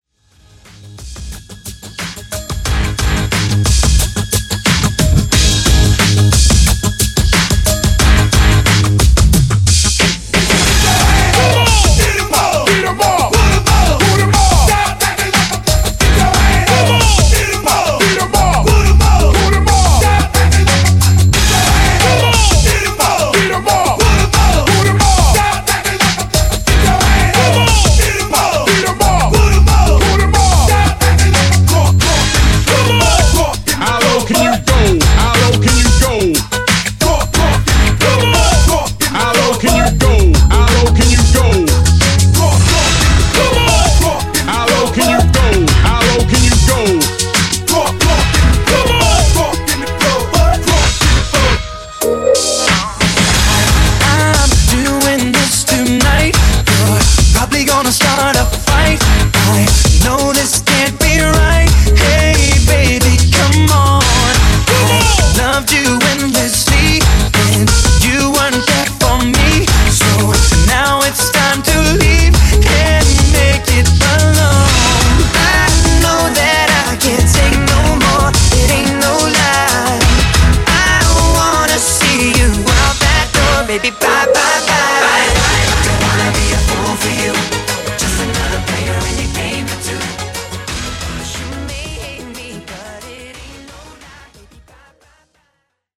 BPM: 90 Time